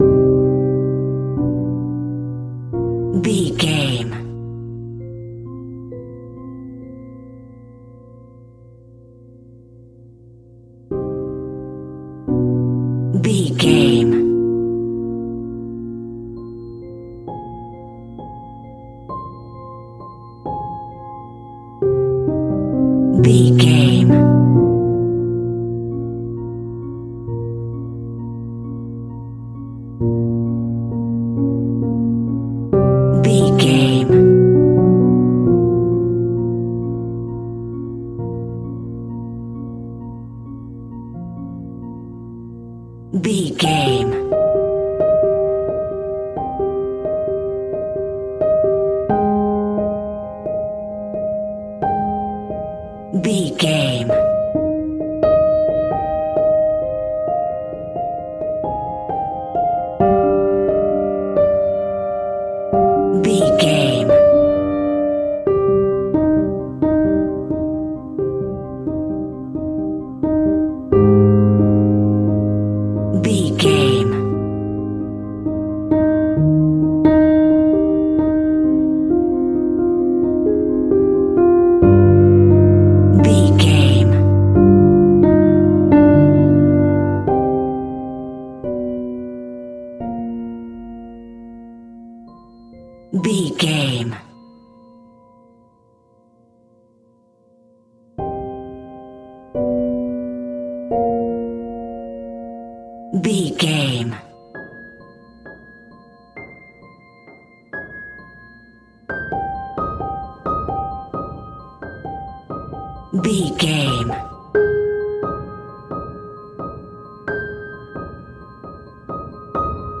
Keyboard Horror Music.
Aeolian/Minor
Slow
ominous
haunting
eerie
horror piano